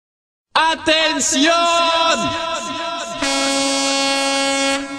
Kermis Geluid Attention
Categorie: Geluidseffecten
Beschrijving: Het Kermis Geluid Attention MP3 biedt je de iconische en energieke geluiden van de Tilburgse Kermis, ideaal voor het creëren van een feestelijke sfeer.
Download het geluid nu en laat de kermis tot leven komen met deze herkenbare, vrolijke klanken die de magie van de kermis direct naar je toe brengen!
kermis geluiden, geluidseffecten
kermis-geluid-attention-nl-www_tiengdong_com.mp3